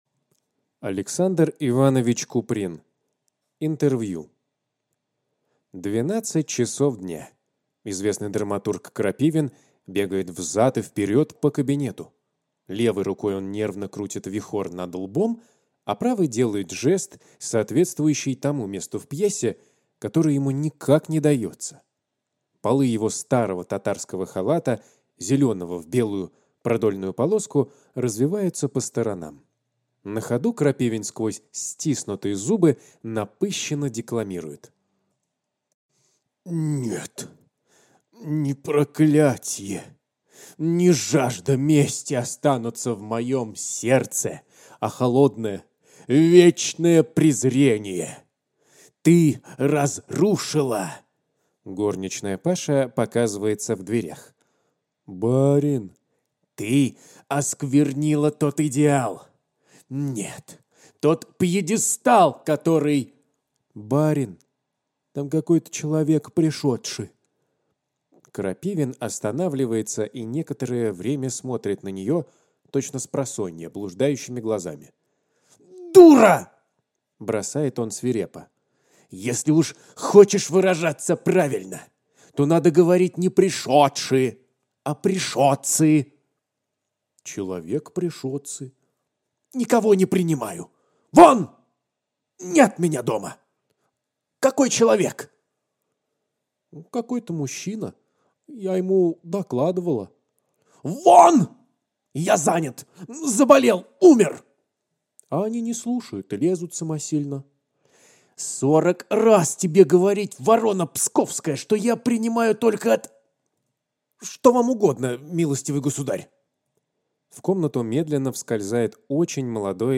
Аудиокнига Интервью | Библиотека аудиокниг